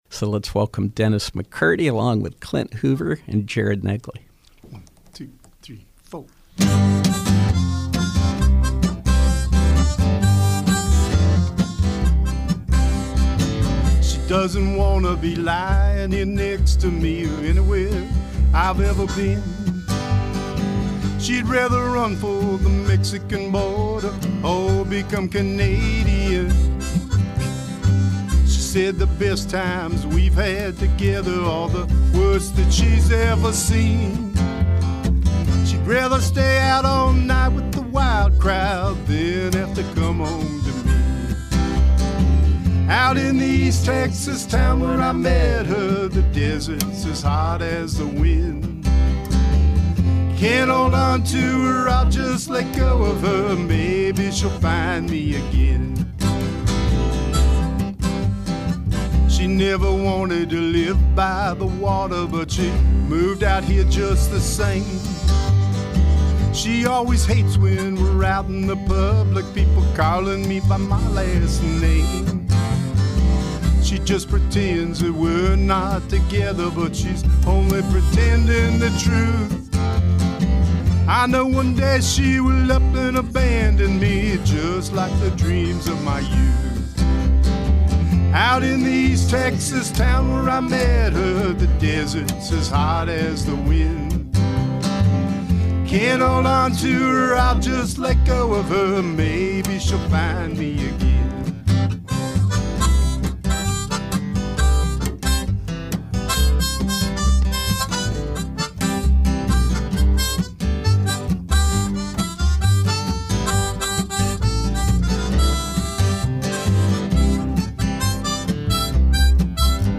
guitar
harmonica
upright bass